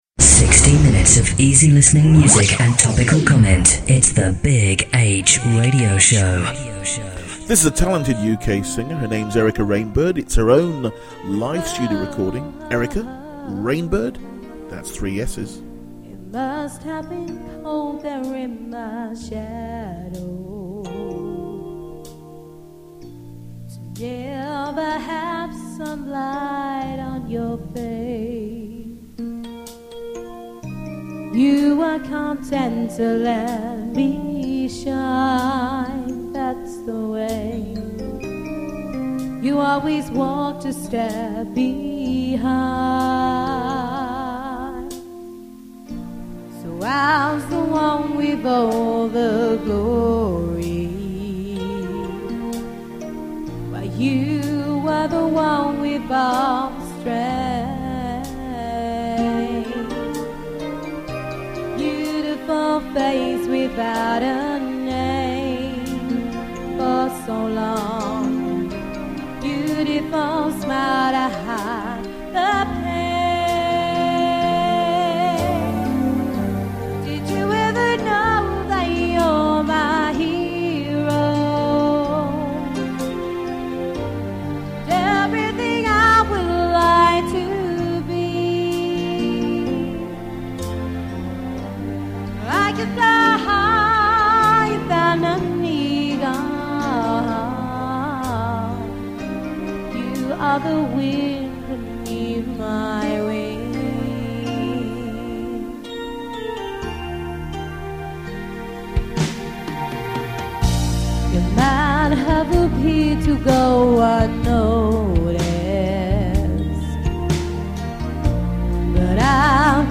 Latest Episode The Big H Radio show sampler Download the latest episode Note: in some browsers you may have to wait for the whole file to download before autoplay will launch. The Big H Radio Show 60 minutes of music, book reviews, world news in overview, new artists singing the oldies, songs from the shows, classic choons and comment on the state of mankind. Easy, easy listening.